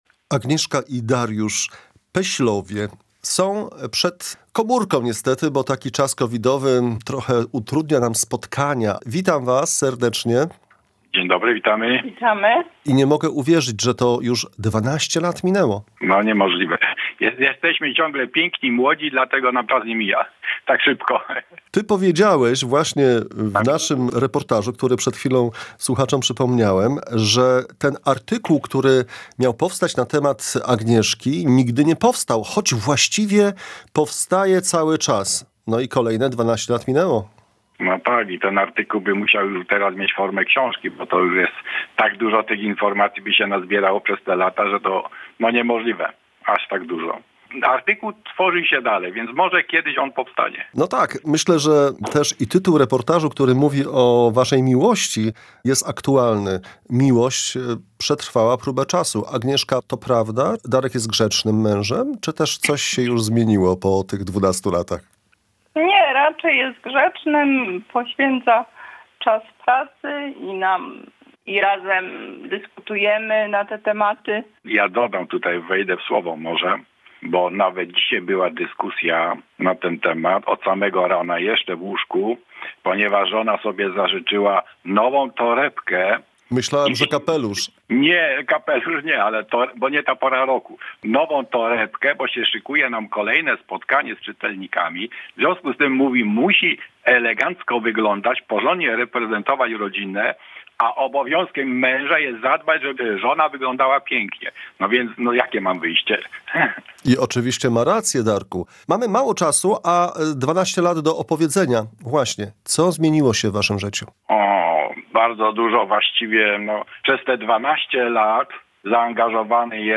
„Oblicza miłości” – posłuchaj reportażu